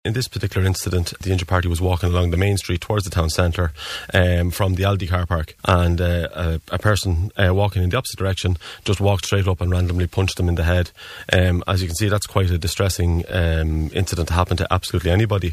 had this appeal on this morning's Kildare Today programme.